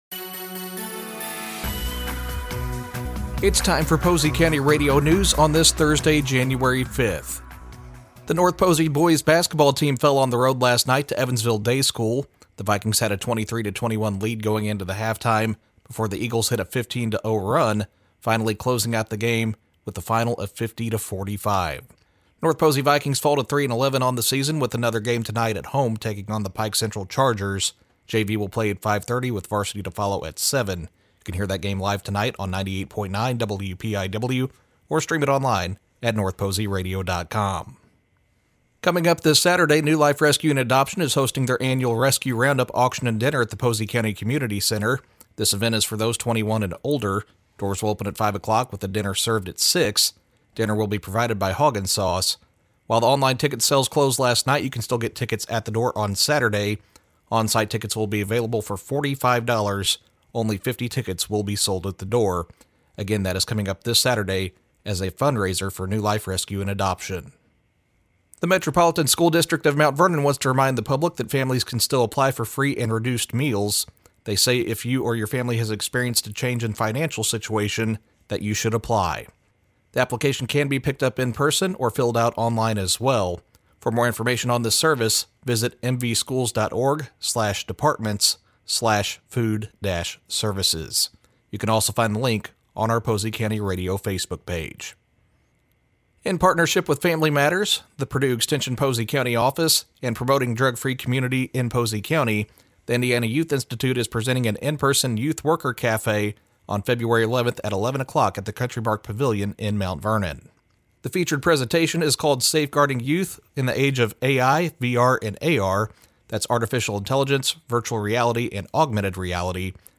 Local News: Thursday February 5th, 2026